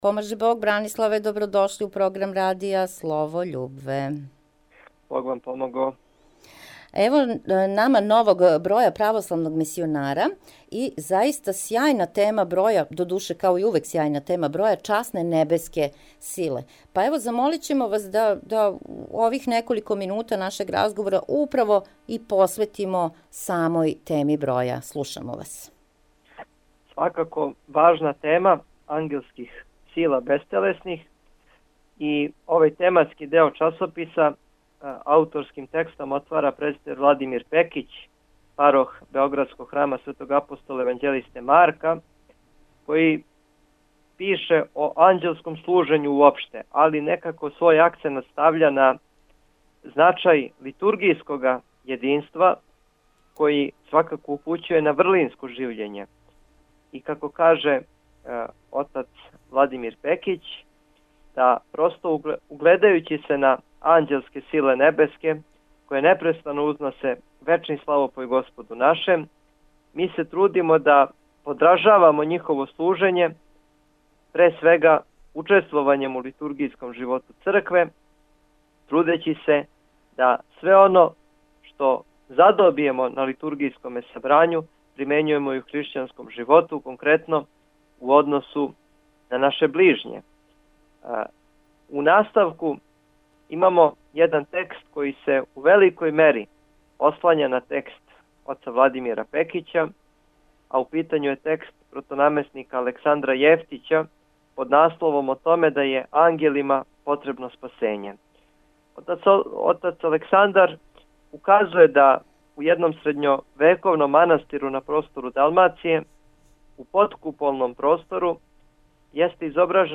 Звучни запис разговора